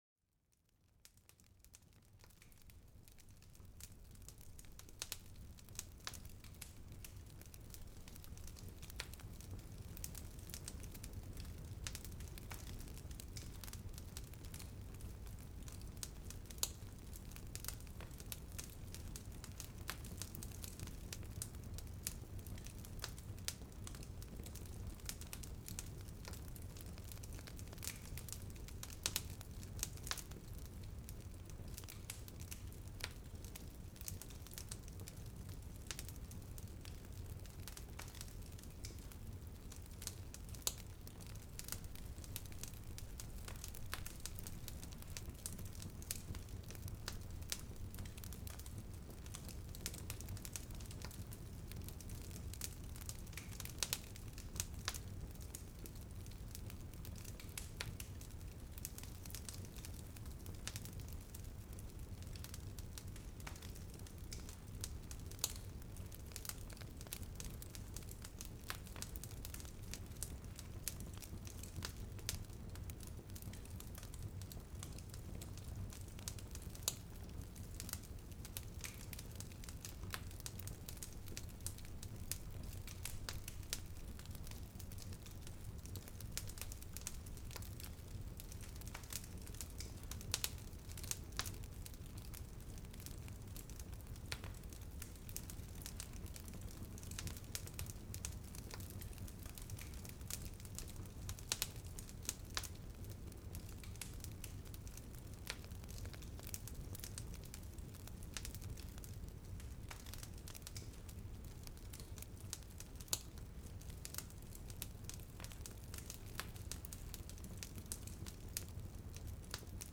El Crepitar del Fuego: Un Momento de Relajación y Calidez para la Mente